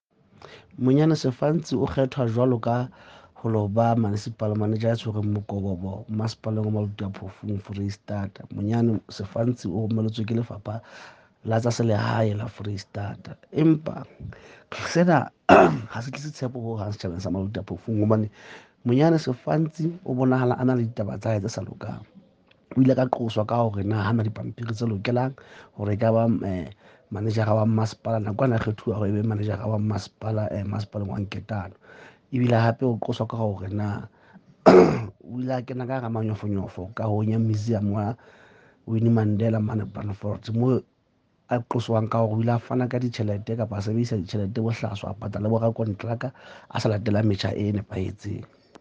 Sesotho soundbites by Cllr Moshe Lefuma.